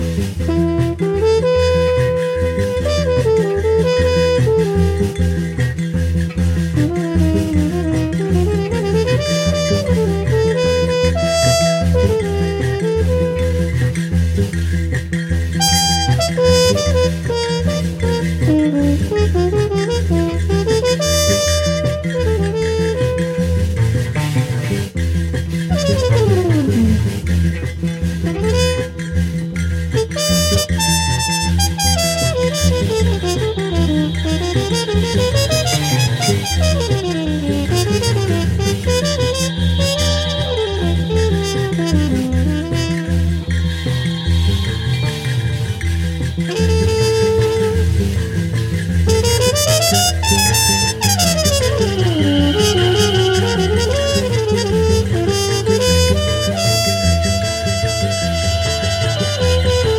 Pharoah Sandersを彷彿とさせる、生々しくタフでありながら優美なサックス。